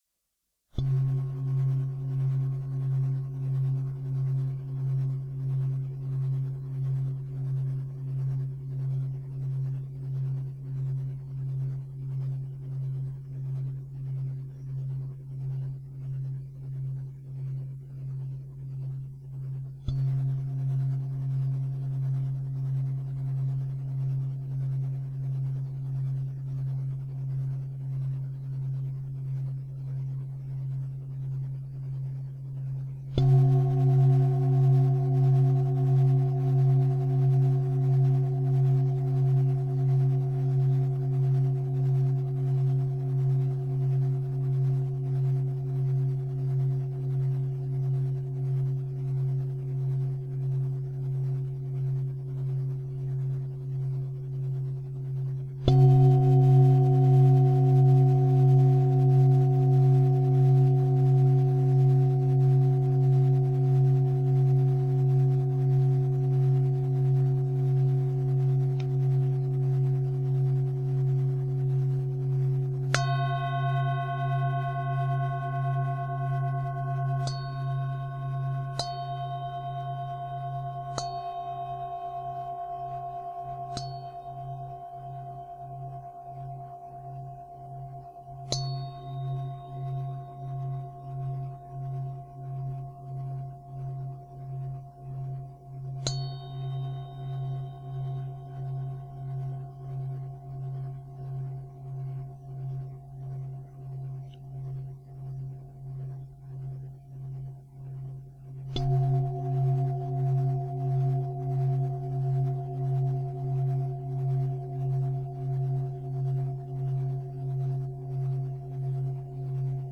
Tibetan Zymbel
TibetanZymbel.wav